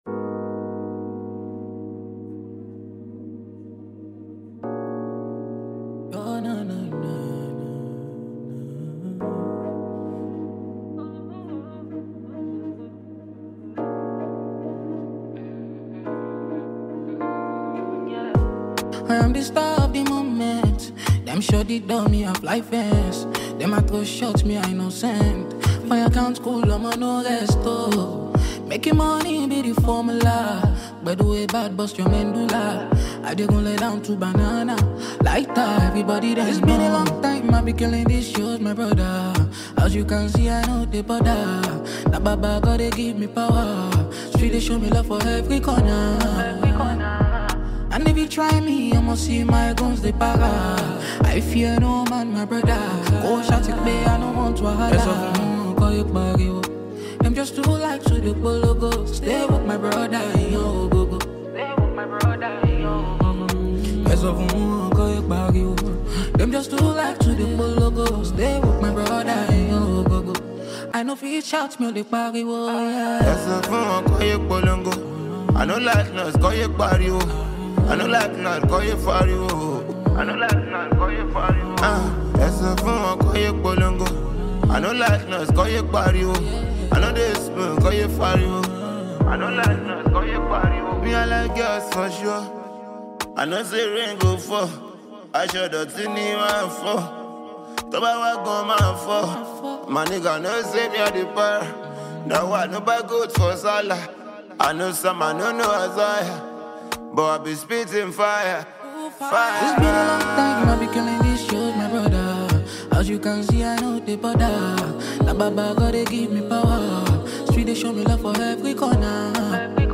Nigerian talented singer